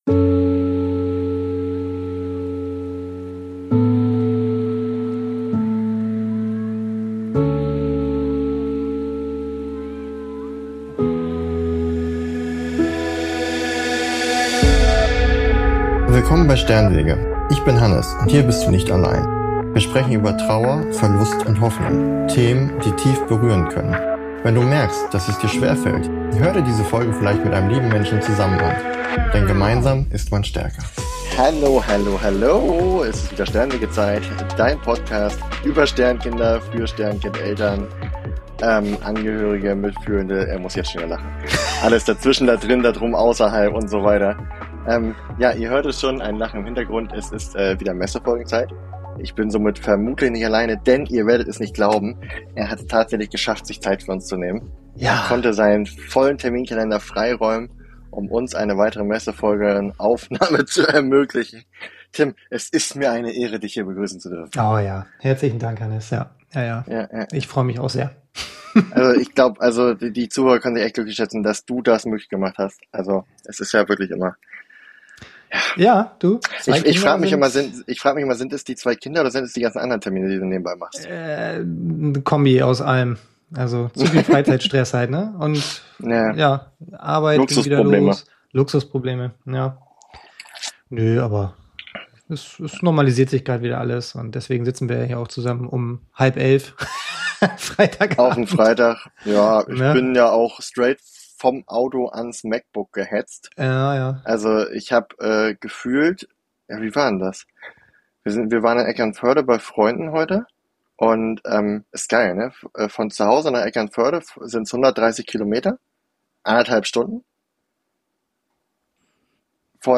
Ein Gespräch über Halt, Verständnis und die Kraft der Gemeinschaft.